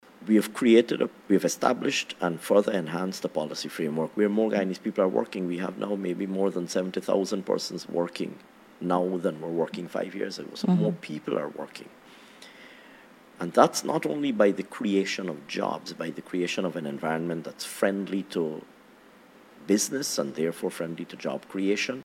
In an interview on the Starting Point Podcast, the Minister noted that Guyana, like the rest of the world, is affected by global price increases, but also reflected on the measures that were rolled out by the government.